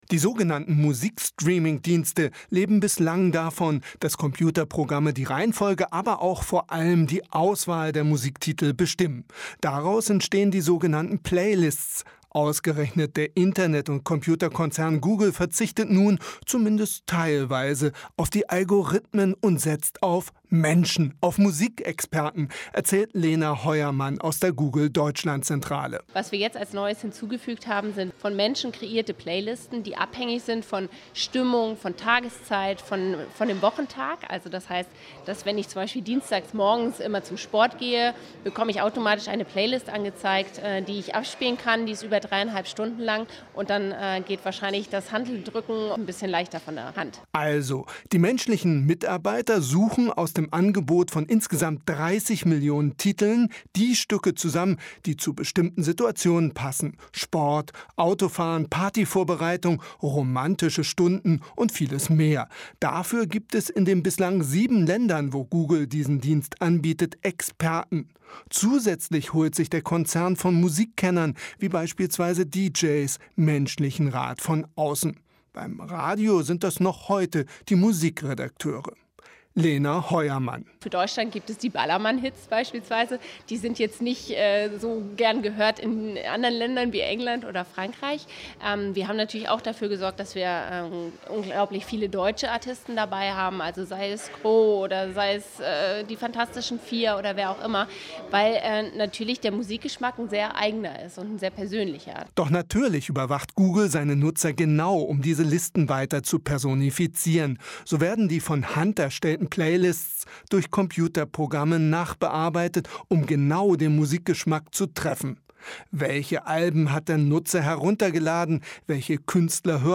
Posted in Computer, Digital, Google, Internet, Medien, Nachrichten, Radiobeiträge, Software, Topthema